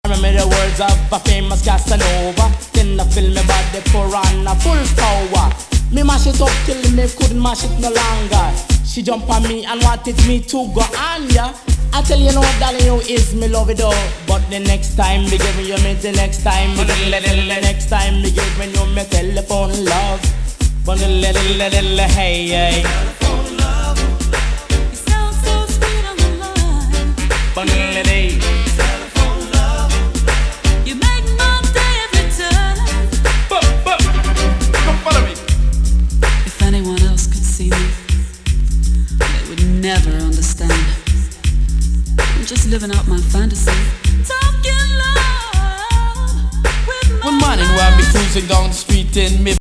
ファットなベースラインがウネりつつも、ユルさ全開のRAGA HIP HOP SIDE-A